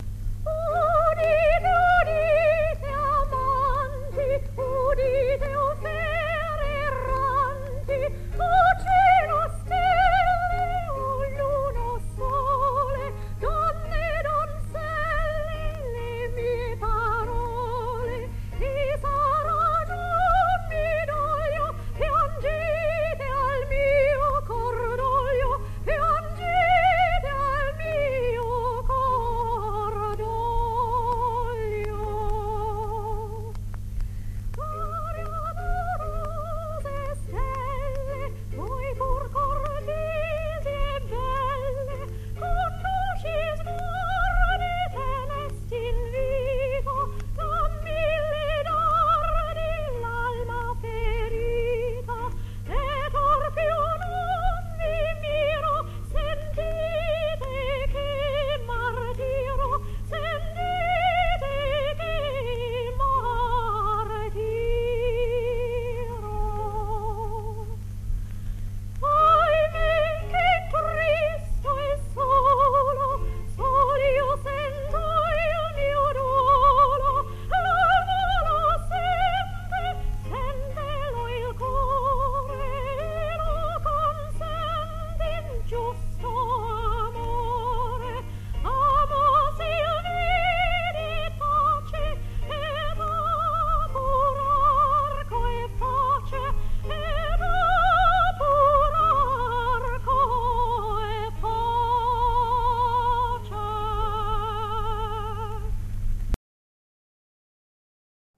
02. Caccini, Udite amanti (aria da Le nuove musiche).mp3